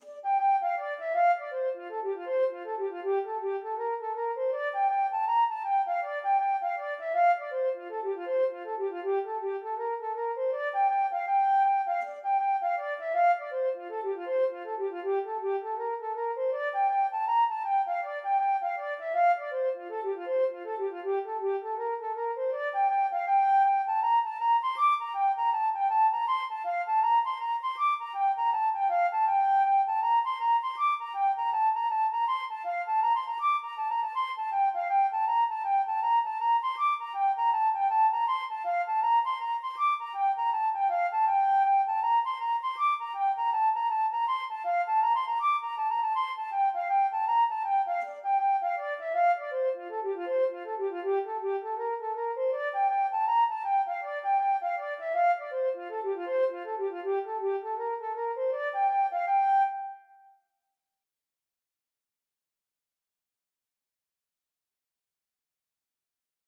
Traditional Trad. The Ships are Sailing (Irish Reel) Flute version
F major (Sounding Pitch) (View more F major Music for Flute )
4/4 (View more 4/4 Music)
F5-D7
Traditional (View more Traditional Flute Music)
Reels
Irish